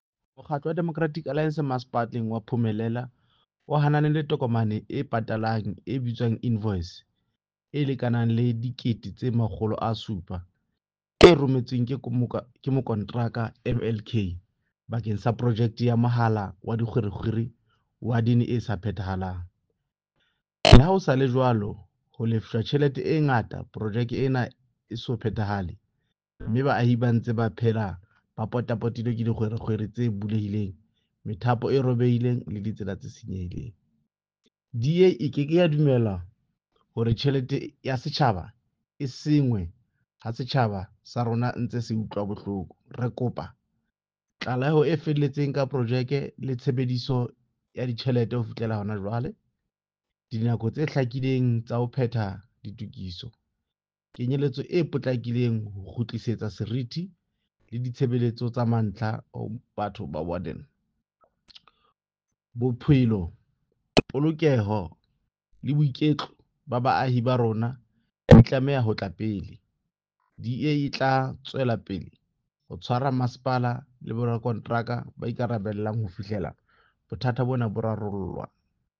Sesotho soundbites by Cllr Diphapang Mofokeng and